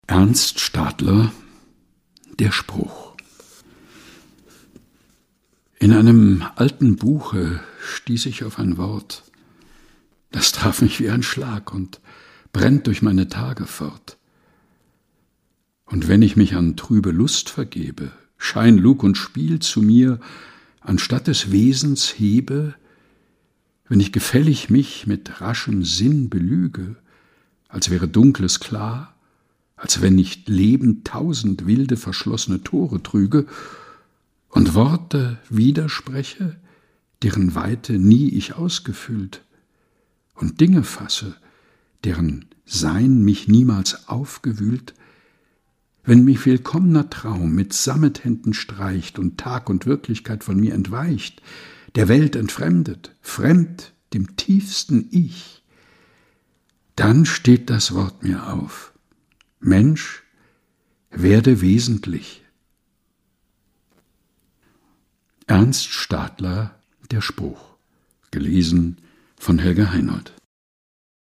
liest: Der Spruch - von Ernst Stadler.